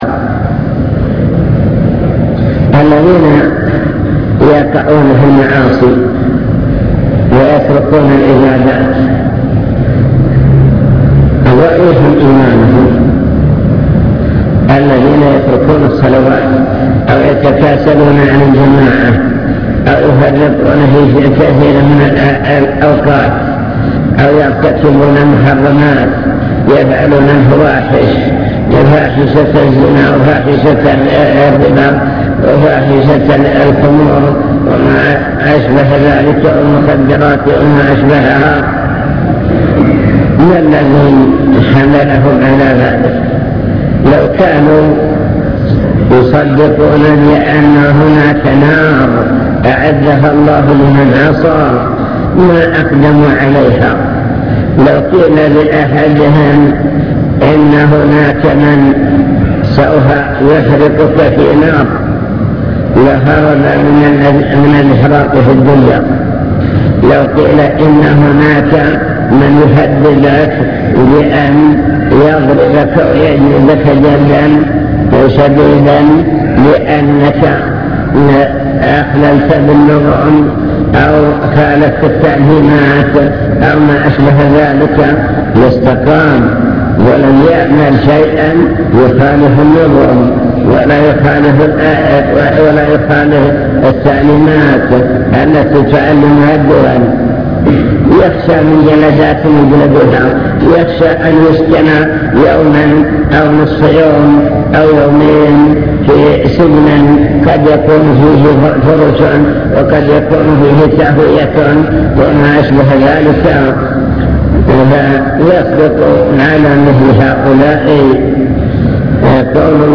المكتبة الصوتية  تسجيلات - محاضرات ودروس  محاضرات عن طلب العلم وفضل العلماء بحث في: أن العمل والتطبيق هو ثمرة العلم